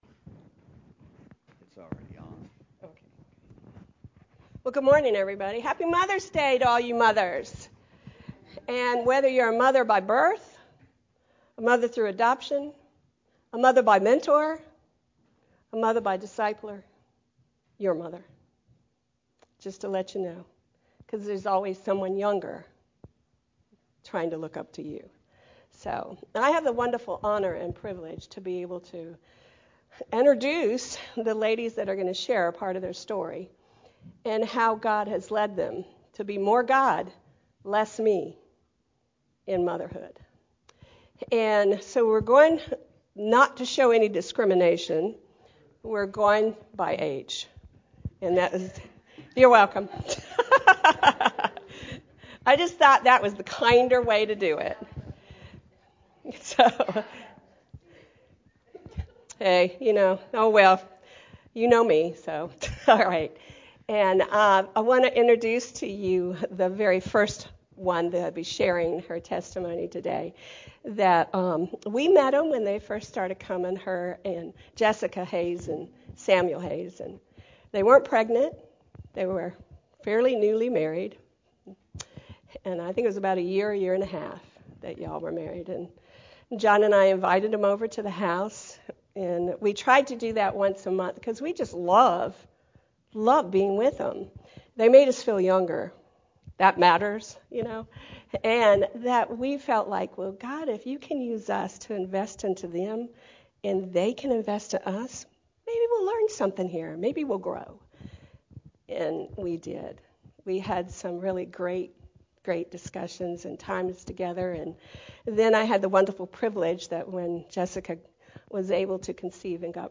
Mother’s Day Testimonies
5-14-23-Mothers-Day-Testimonies-CD.mp3